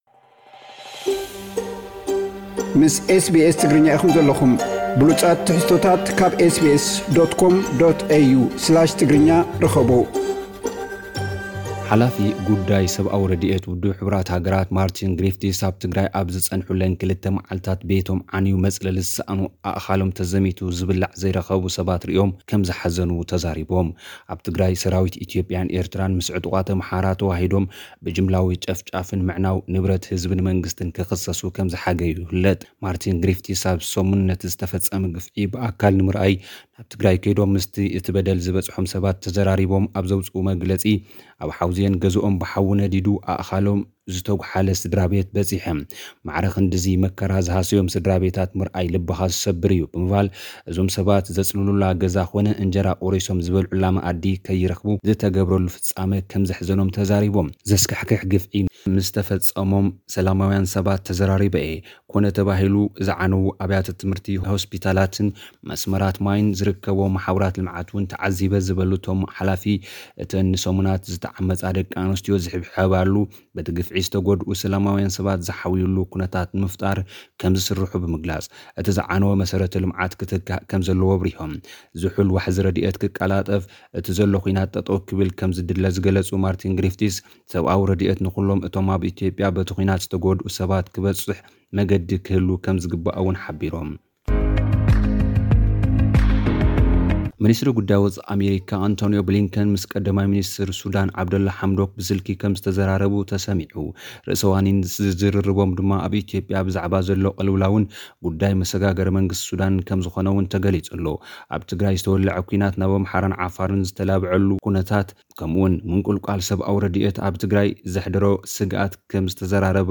ሓላፊት ዓለምለኻዊ ትካል ረድኤት ኣሜሪካ ሳማንታ ፓወር ምስ ቀዳማይ ሚንስተር ኢትዮጵያ ኣብይ ኣሕመድ ክትዘራረብ ዝተትሓዘ መደብ ከም ዘይሰለጠ ብምግላጽ መንግስቲ ኢትዮጵያ ኣንጻር ተጋሩ ዘቕንዑ ጎስጓሳት ጽልኢ ከም ዝተጸምደ ከሲሳ። ዝብሉ ኣርእስታት ዝሓዘ ጸብጻብ ልኡኽና ኣብዚ ቀሪቡ ኣሎ።